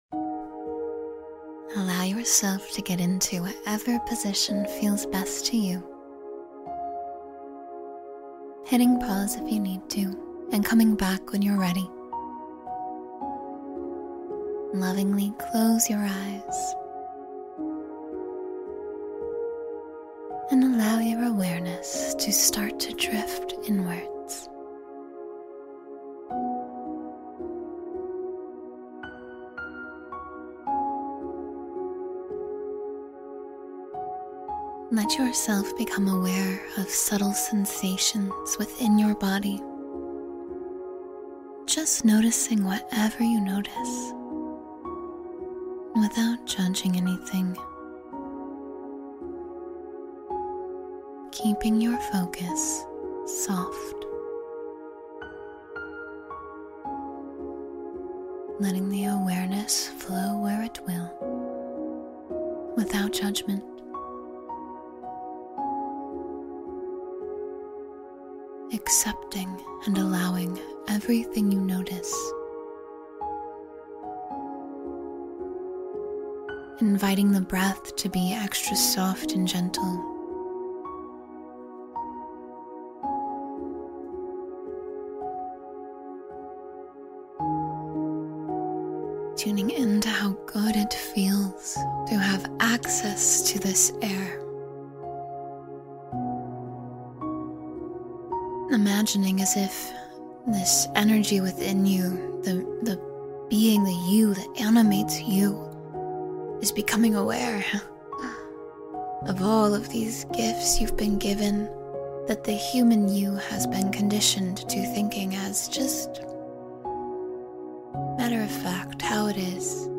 Your Life Transforms Once You Realize This Truth — Guided Meditation for Awakening